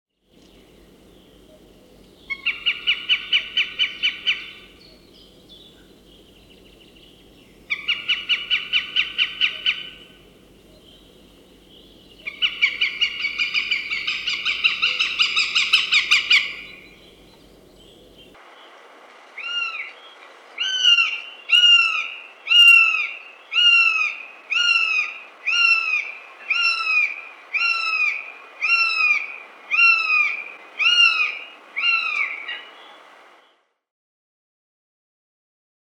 Звуки ястреба
Ястреб клекочет среди деревьев